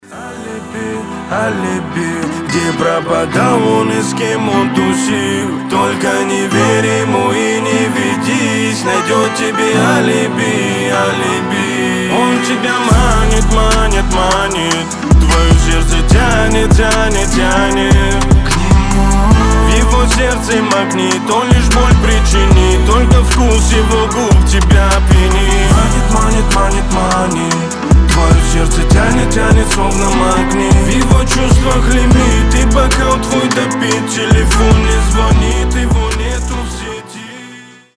• Качество: 320, Stereo
лирика
грустные